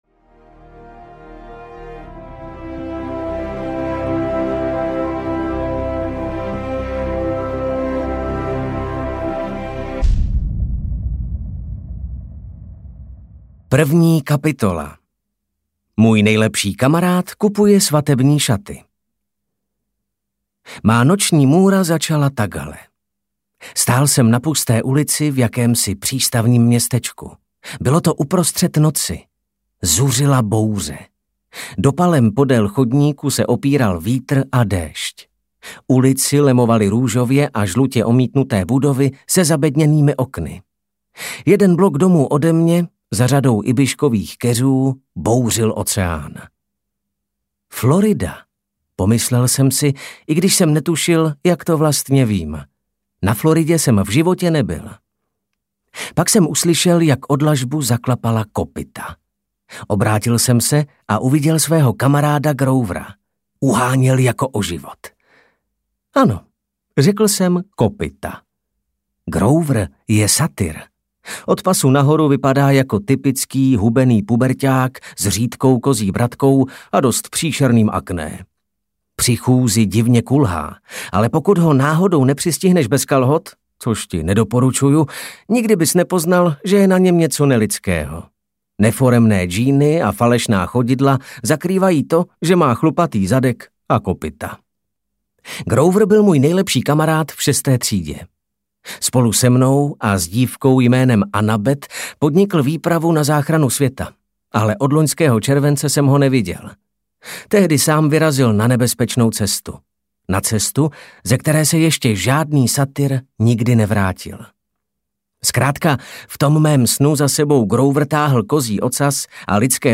Percy Jackson - Moře nestvůr audiokniha
Ukázka z knihy